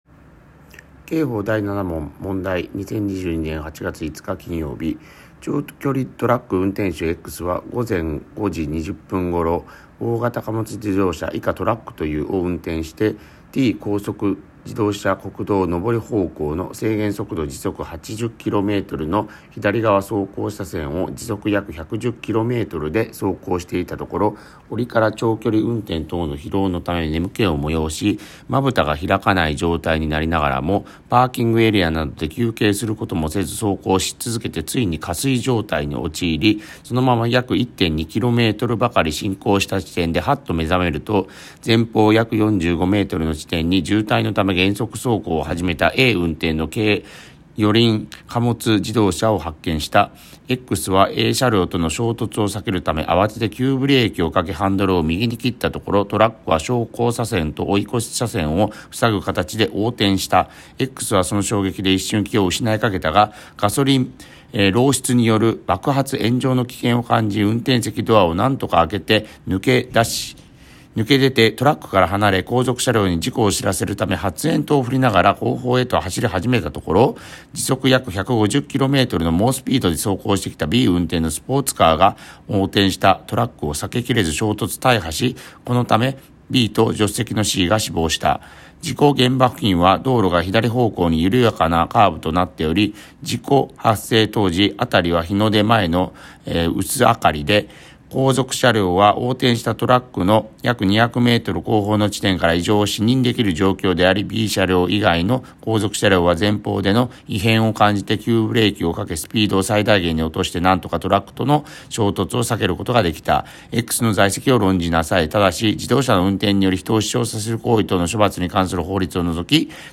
問題解答音読